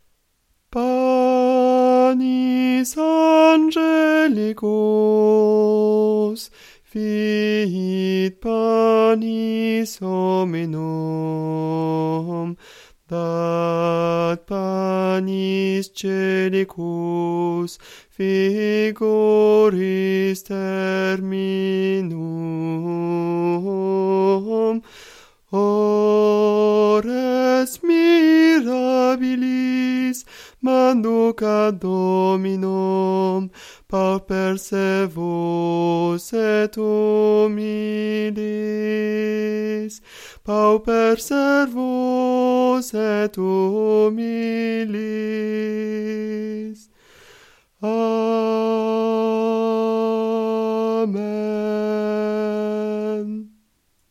Voix chantée (MP3)COUPLET/REFRAIN
TENOR